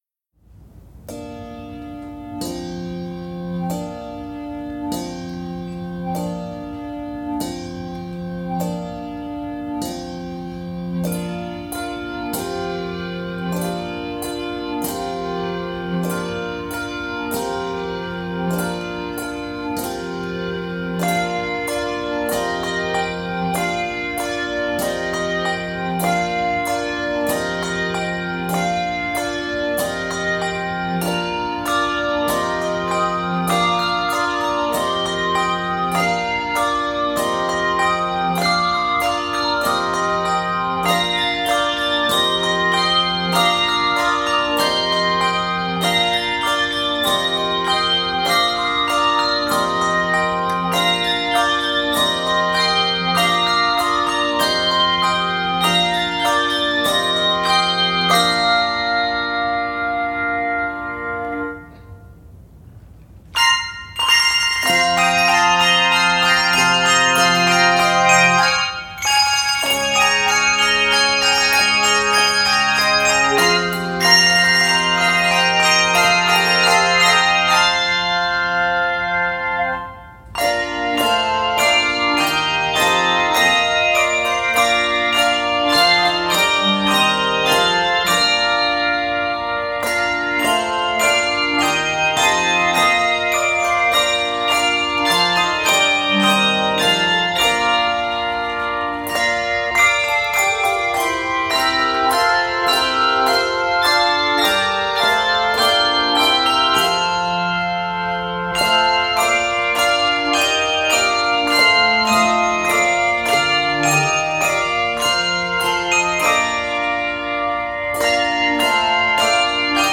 It may also be played by handbells alone.
Key of Bb Major.